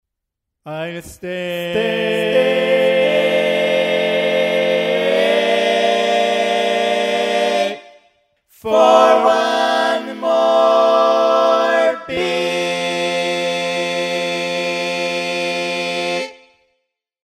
Key written in: F Major
Type: Barbershop